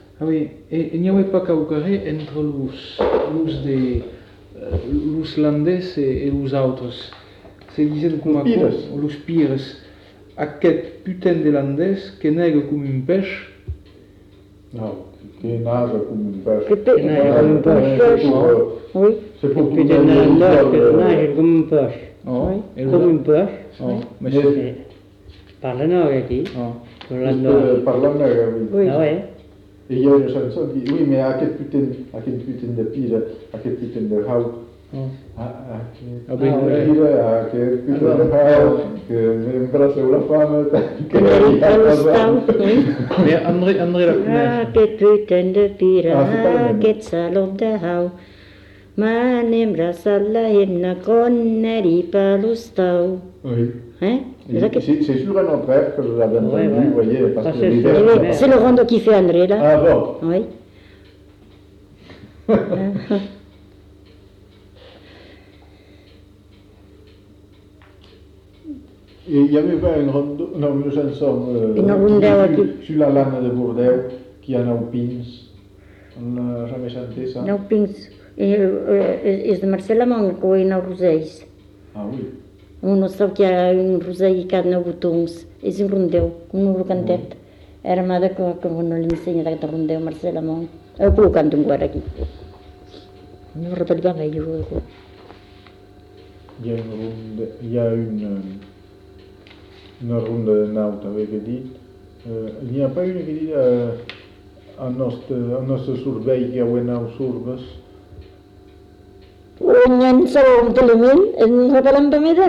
Aire culturelle : Bazadais
Lieu : Cazalis
Genre : chant
Effectif : 1
Type de voix : voix de femme
Production du son : chanté
Danse : rondeau